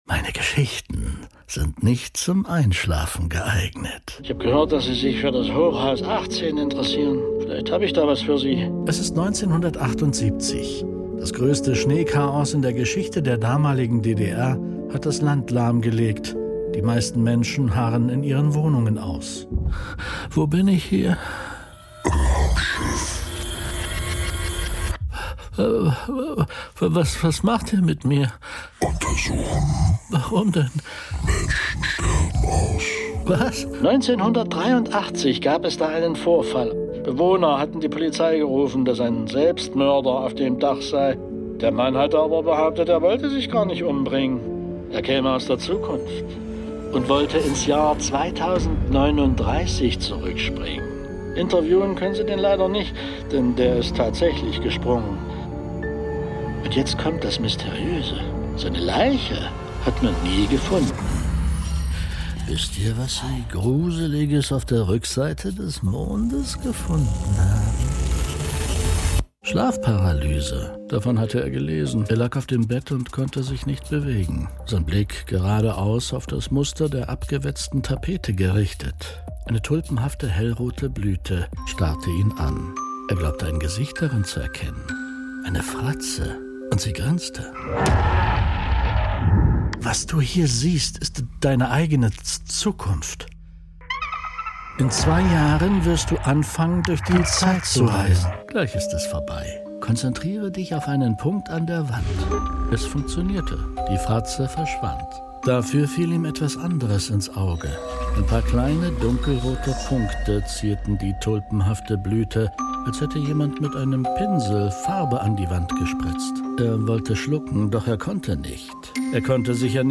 Modern Mystery und psychologische Fiktion
Jede Episode ist eine immersive Solo-Performance.